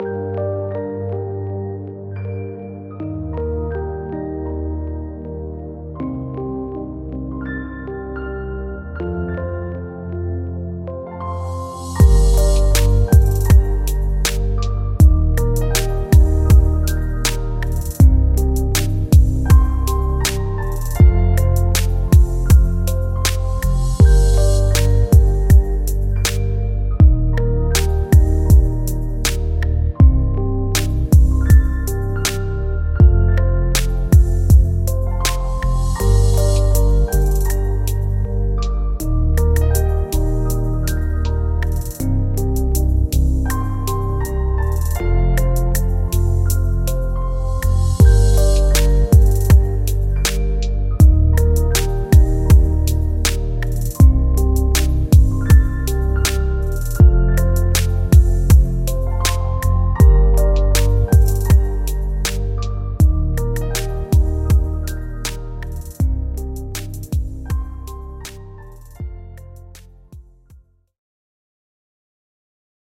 Genre: Lo-Fi